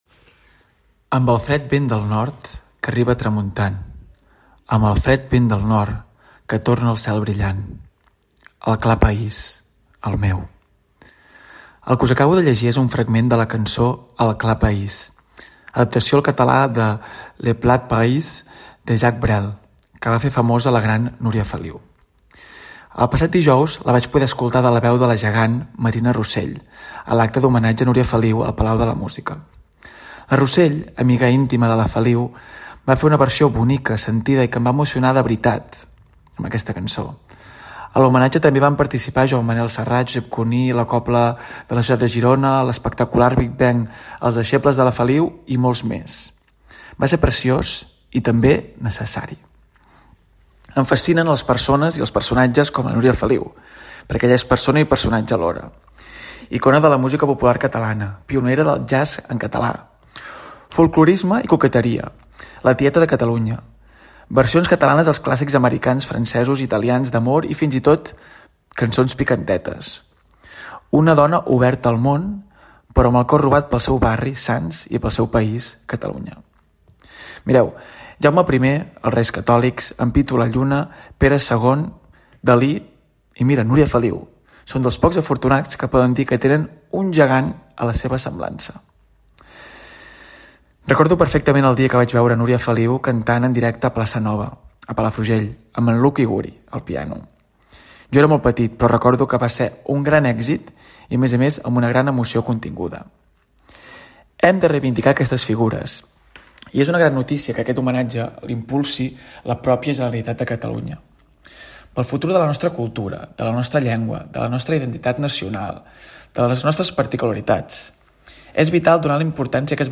Opinió